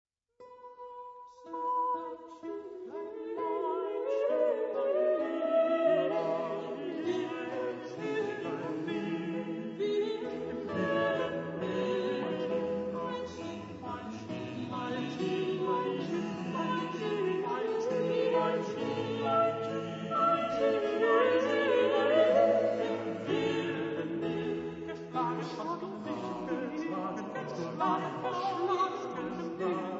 liuto
• Registrazione sonora musicale